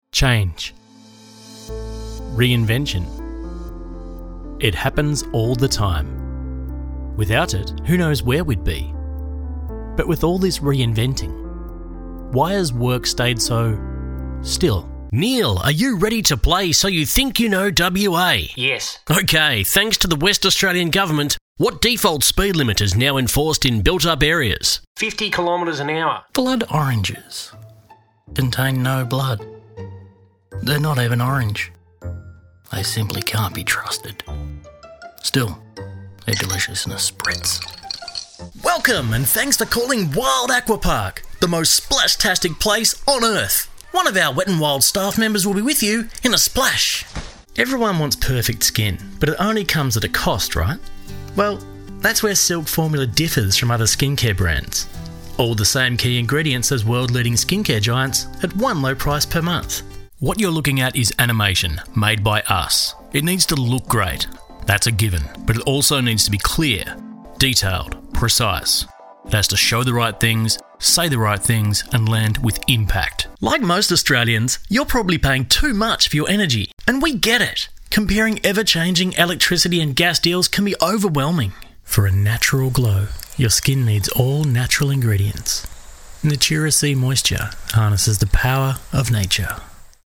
Professional Male Voice Over Talent | Professional Male Voice | Cheap Voiceover
English (Neutral - Mid Trans Atlantic)